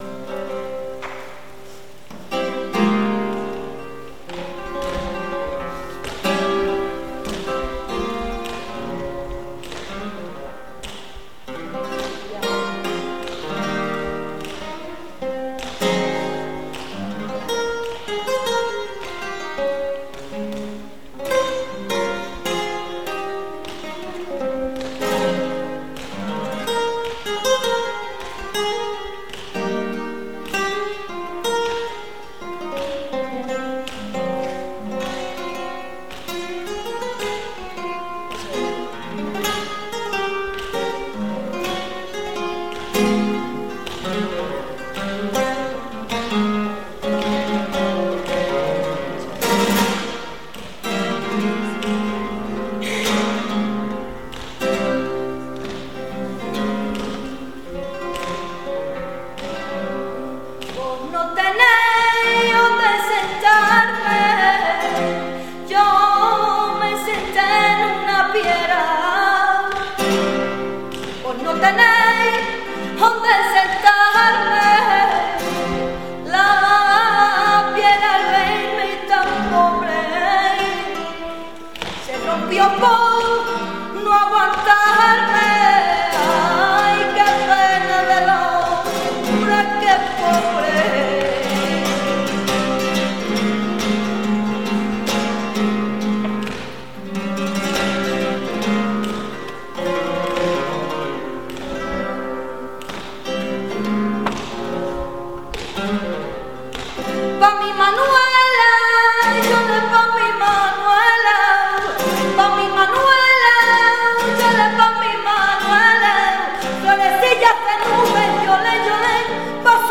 Concierto Flamenco
Auditorio Fonseca, Salamanca
Fandangos de Huelva